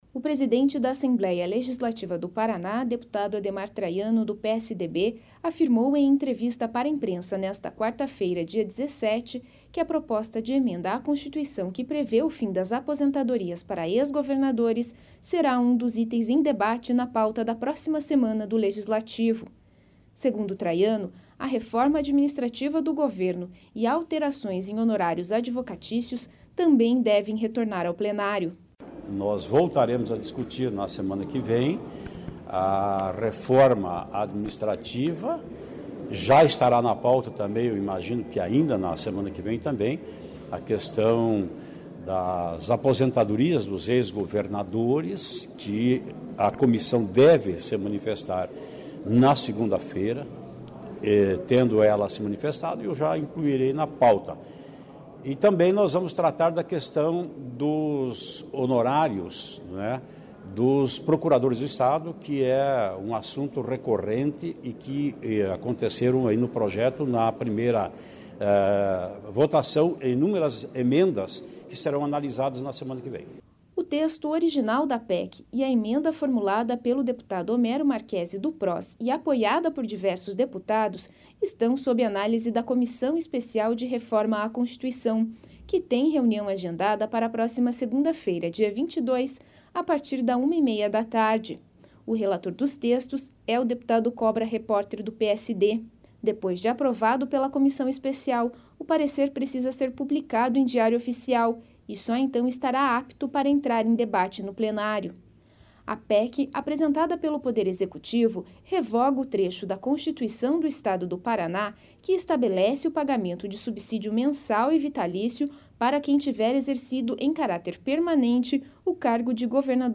O presidente da Assembleia Legislativa do Paraná, deputado Ademar Traiano (PSDB), afirmou em entrevista para a imprensa nesta quarta-feira, dia 17, que a Proposta de Emenda à Constituição (PEC) que prevê o fim das aposentadorias para ex-governadores será um dos itens em debate na pauta da próxima se...